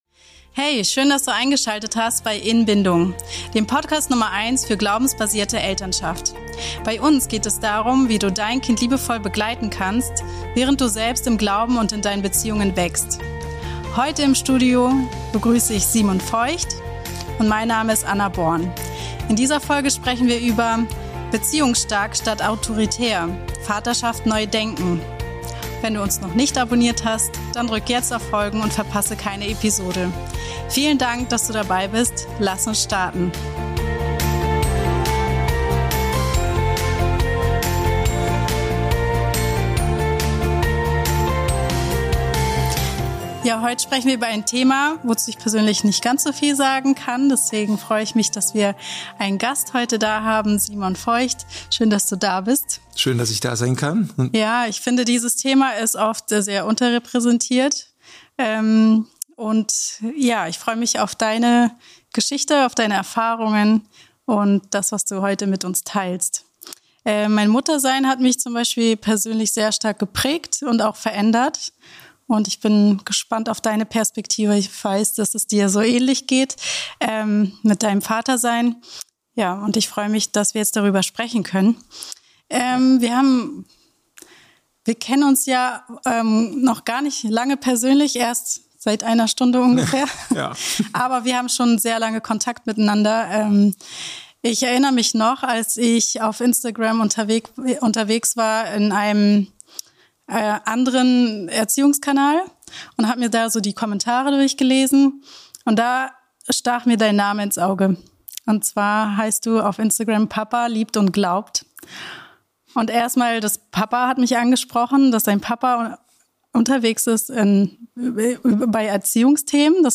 Wir sprechen mit einem Vater, der neue Wege geht.